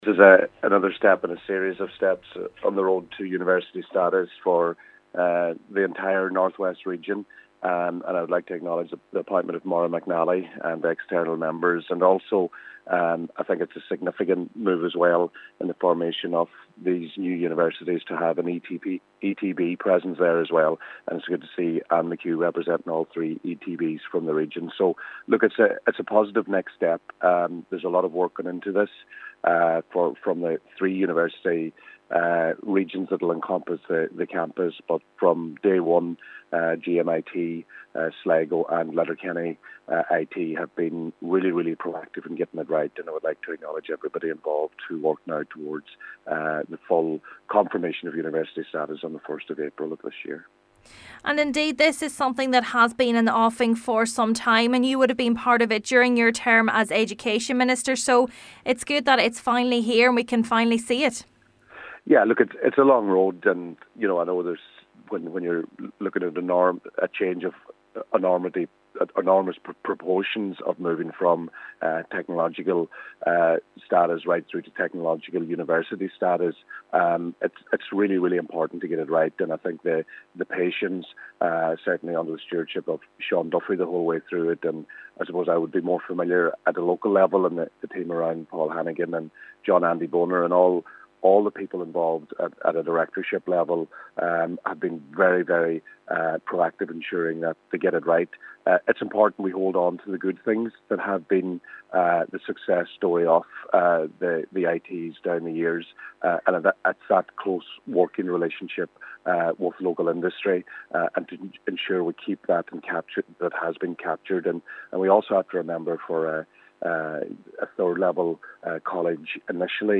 Donegal Deputy Joe McHugh says it is a significant step in the journey of the Technological University: